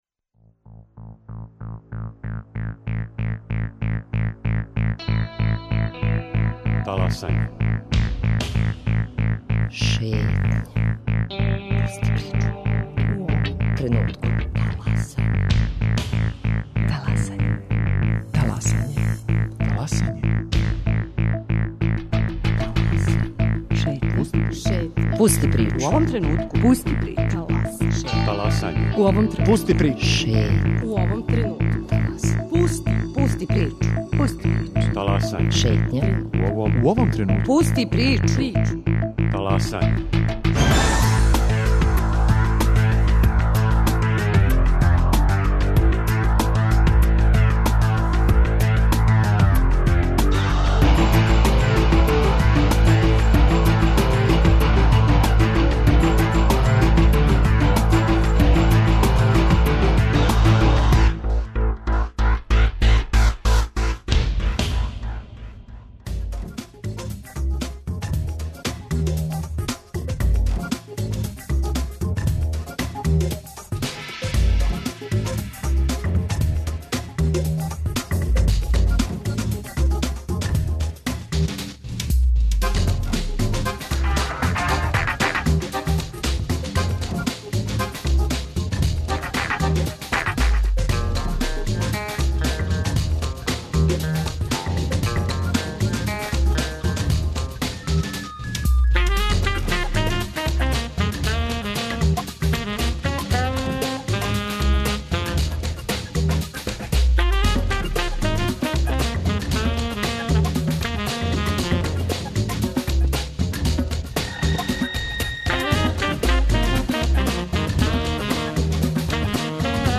На телефонској линији уживо из Косовске Митровице биће државни секретар Министарства за Косово и Метохију Оливер Ивановић, а чућете и прилоге наших дописника са терена.